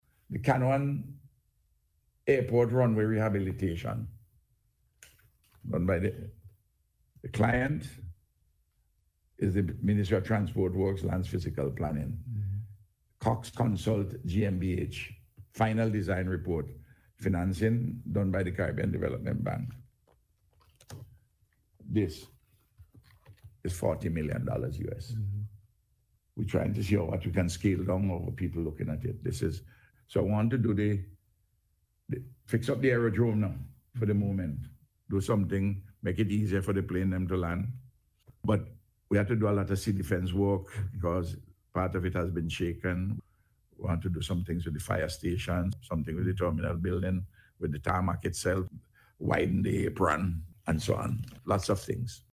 This was among a range of issues addressed by Prime Minister, Dr. Ralph Gonsalves on Radio on Sunday.